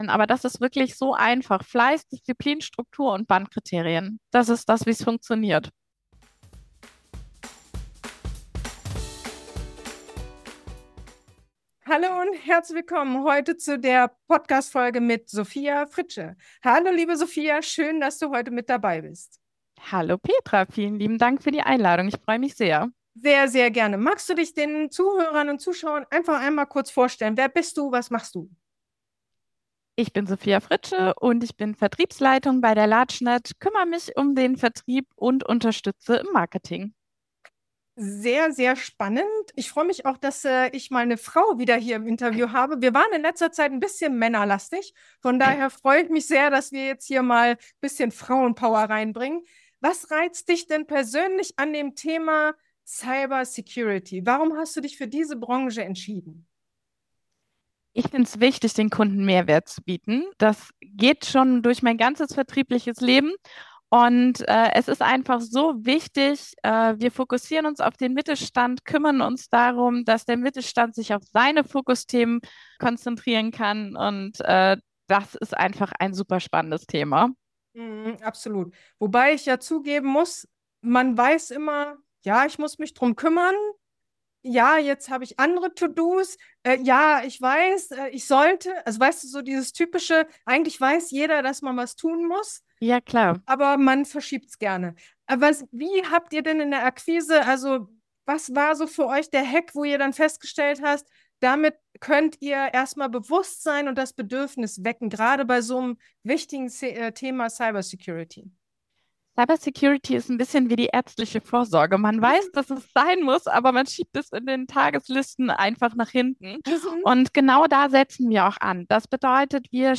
B2B-Akquise in der IT-Sicherheit: Vertrauen vor Verkauf | Interview